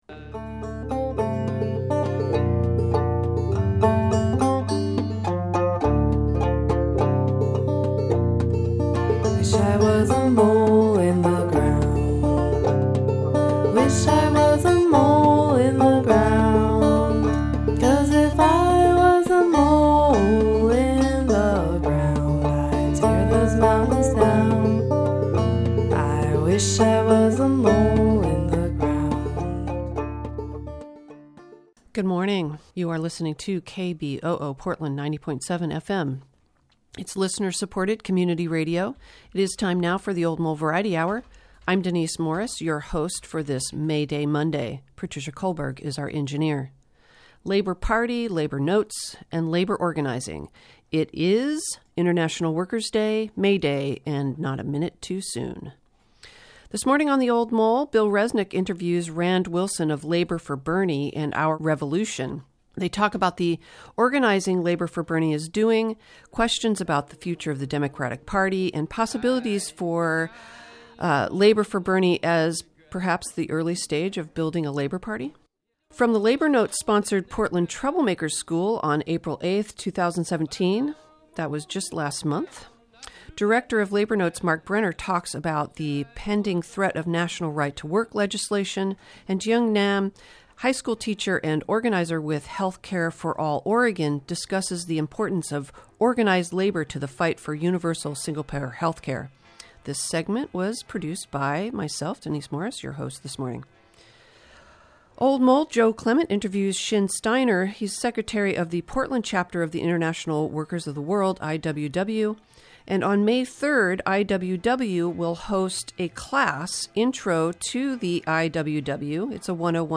and Our Revolution about the central role labor must play in contemporary politics. 2. Two talks from the Portland Troublemakers' School about the spread of "right to work" laws and the fight for universal health care.
introductory class on the Wobblies. 4. Voices from Saturday's climate march in Portland.